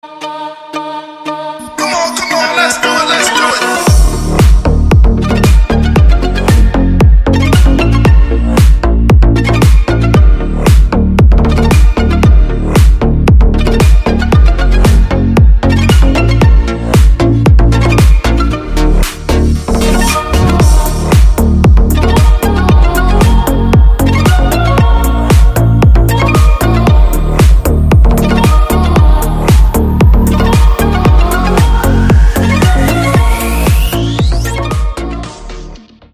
Танцевальные рингтоны
Клубные рингтоны